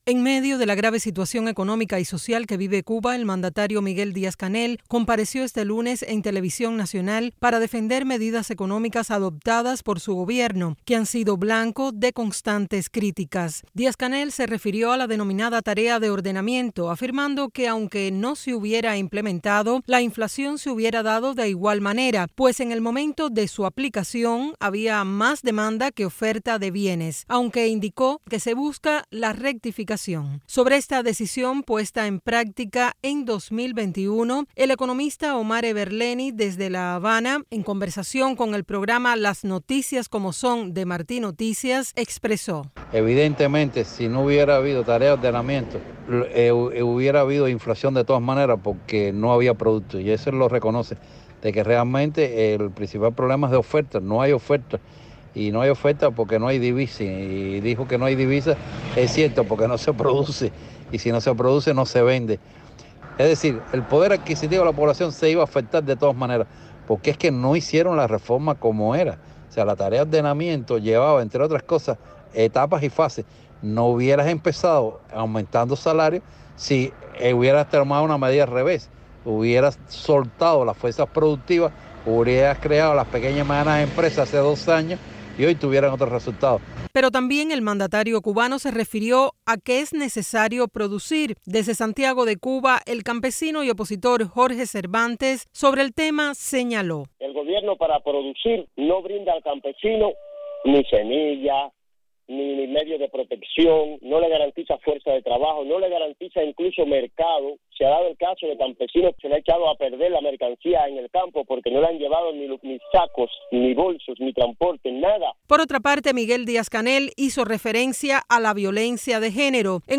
Un economista, un campesino y una activista opinan sobre las declaraciones de Díaz-Canel